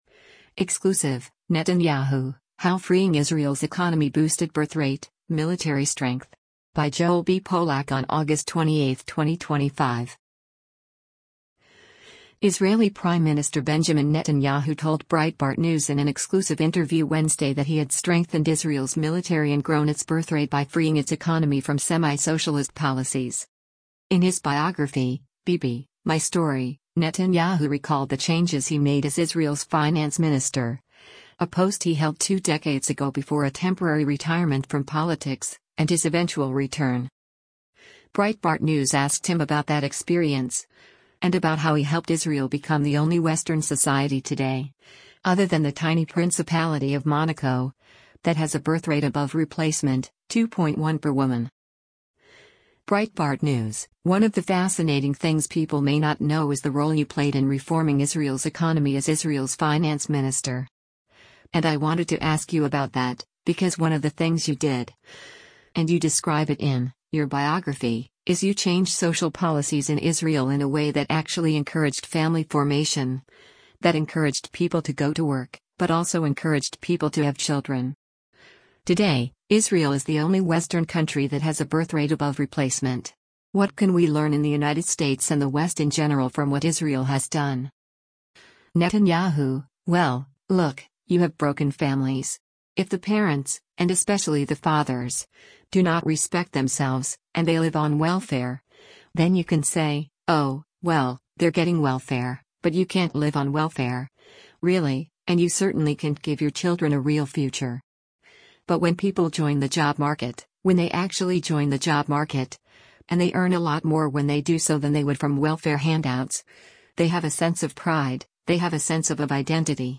Israeli Prime Minister Benjamin Netanyahu told Breitbart News in an exclusive interview Wednesday that he had strengthened Israel’s military and grown its birth rate by freeing its economy from “semi-socialist” policies.